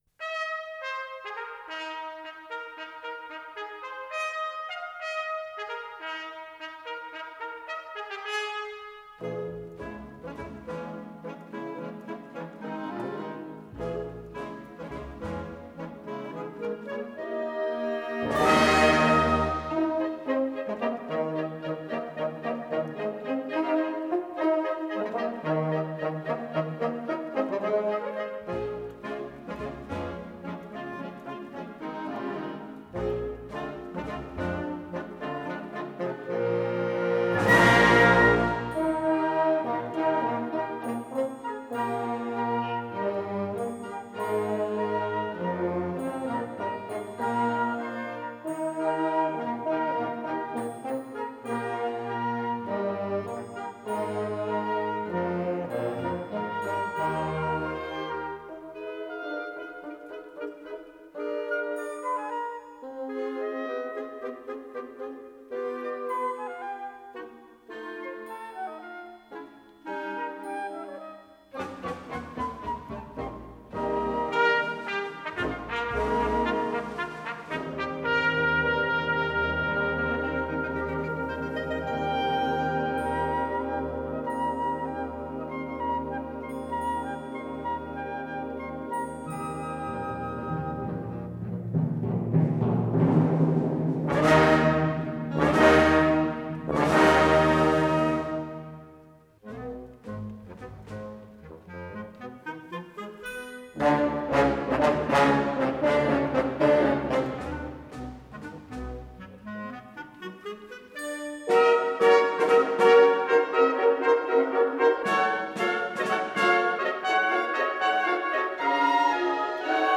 Harmonie